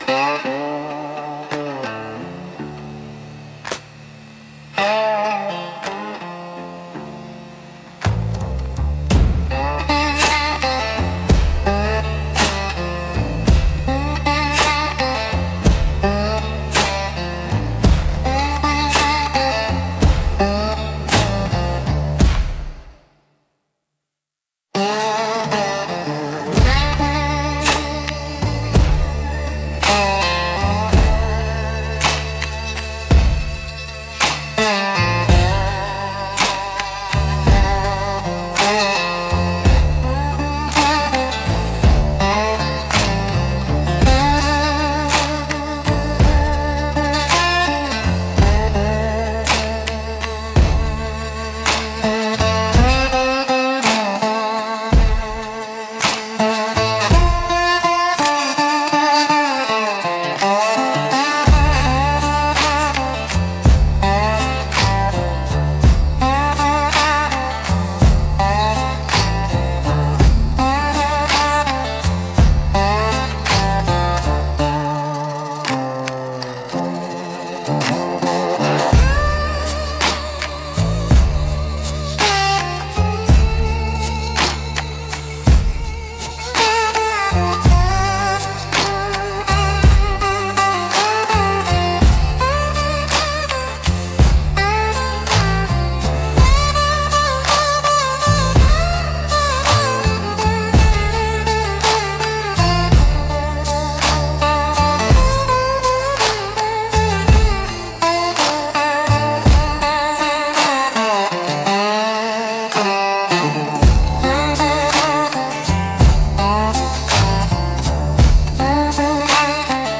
Instrumental - How to Burn It 2.14